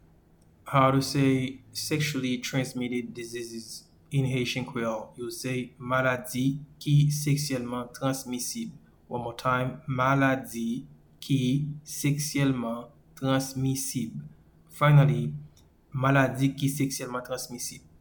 Pronunciation:
Sexually-transmitted-diseases-in-Haitian-Creole-Maladi-ki-seksyelman-transmisib.mp3